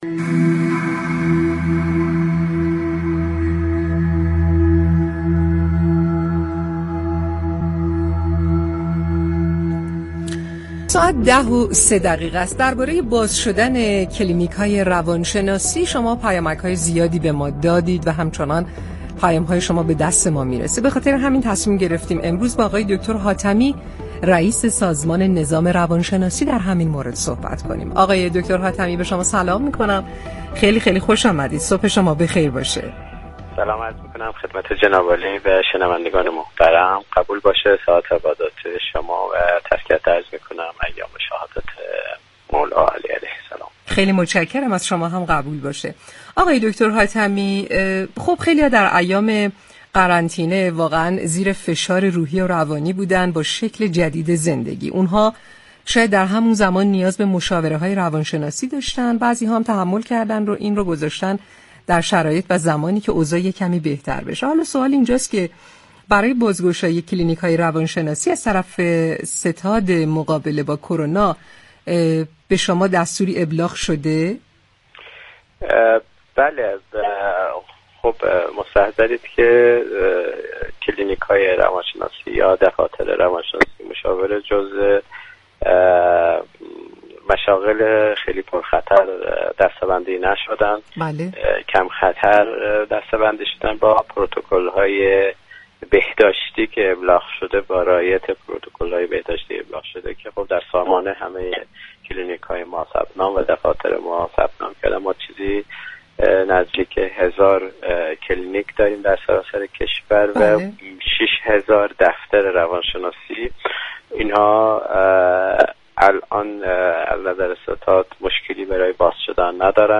دكتر محمدحاتمی، رئیس سازمان نظام روانشناسی در گفتگو با تهران كلینیك از افزایش 20 درصدی تعرفه خدمات مشاوره و روانشناسی در سال جاری خبر داد.